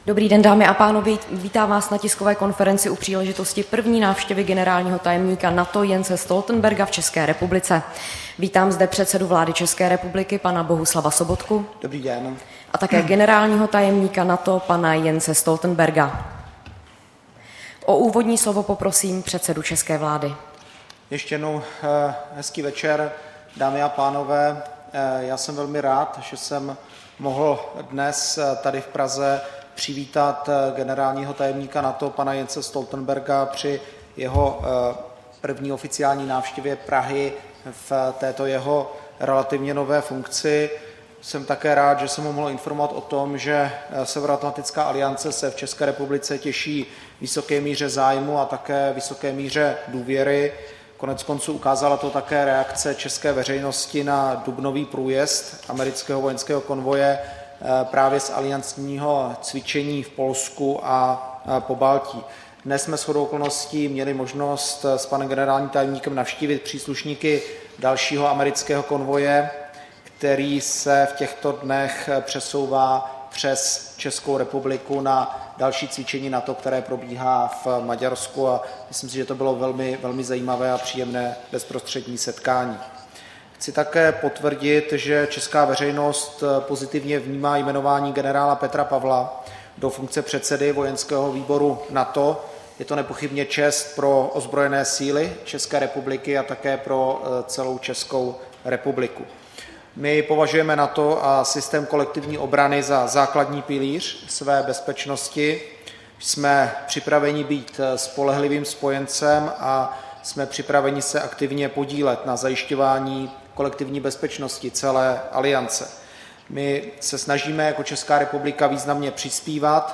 Tisková konference po setkání premiéra Bohuslava Sobotky s generálním tajemníkem NATO Jensem Stoltenbergem